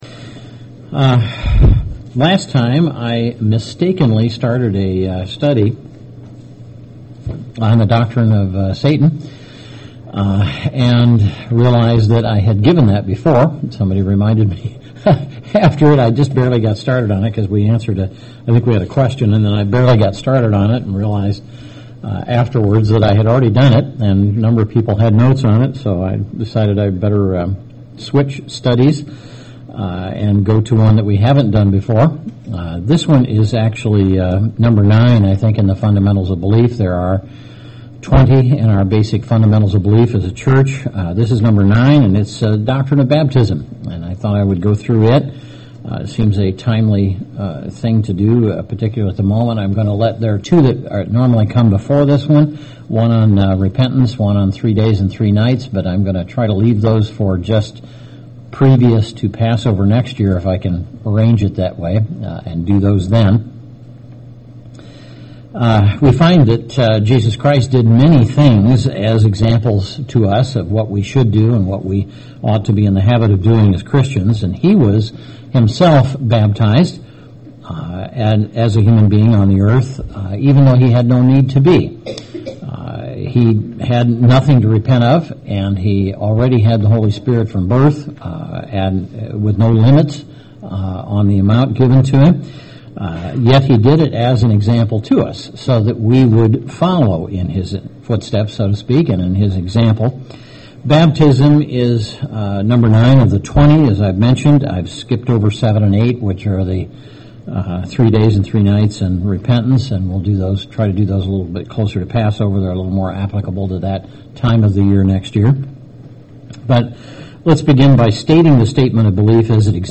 Topical Bible Study Doctrine of Baptism Part One Review of basic beliefs about baptism of adults after repentance